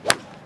hit_hard2.wav